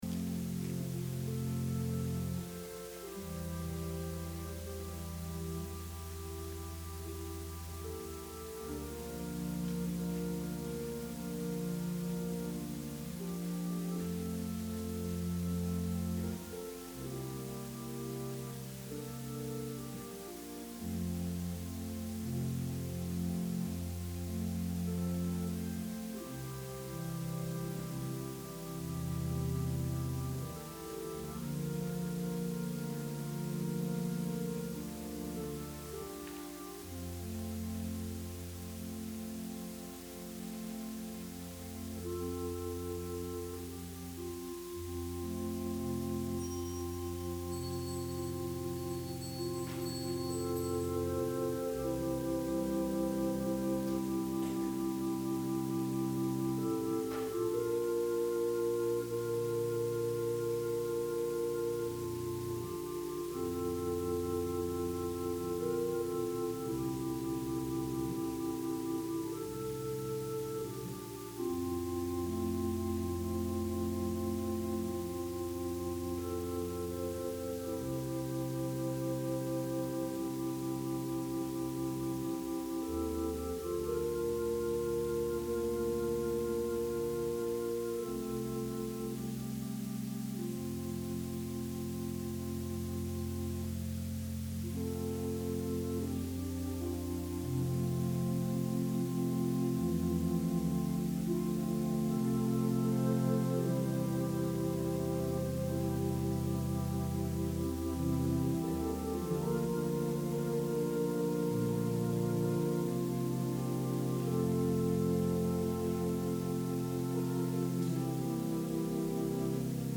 Sermon – November 1, 2020